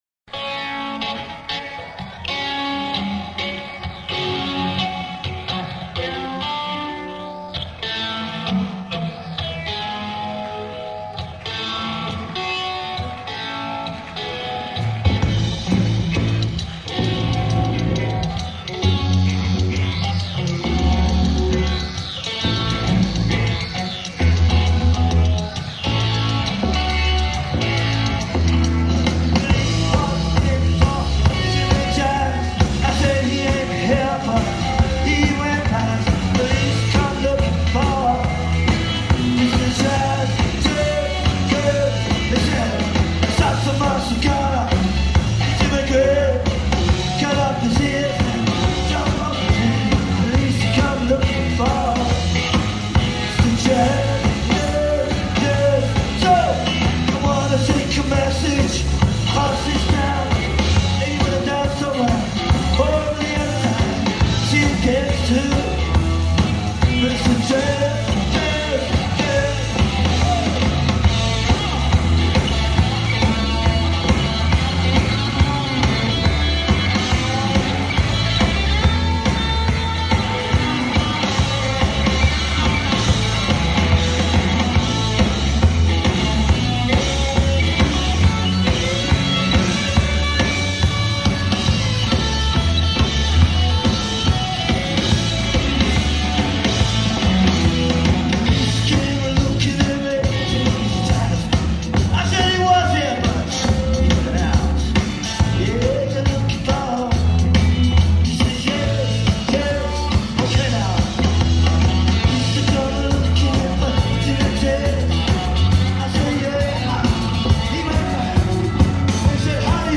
Hammersmith Punk